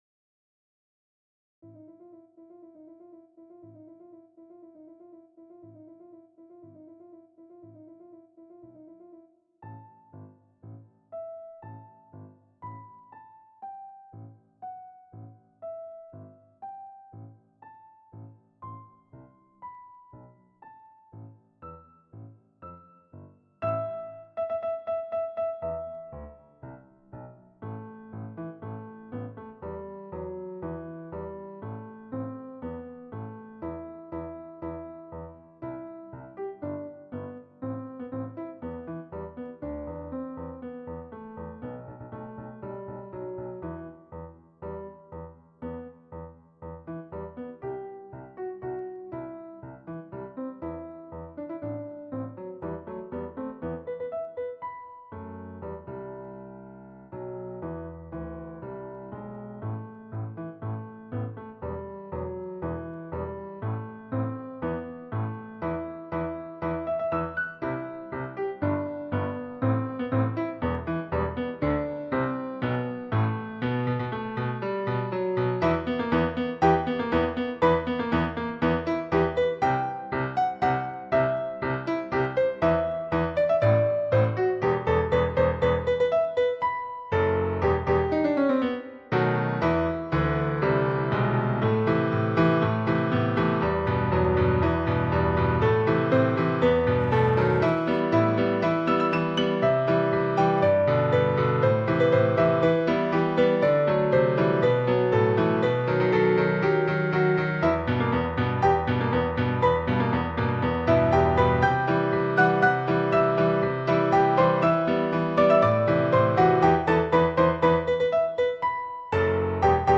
Ноты для фортепиано.
*.mp3 - аудио-файл для прослушивания нот.